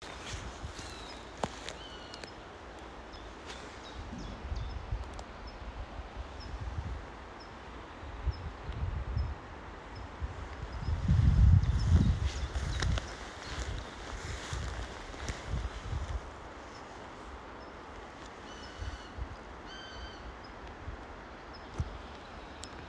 Peļu klijāns, Buteo buteo
Administratīvā teritorijaĀdažu novads
PiezīmesLigzdā sauc.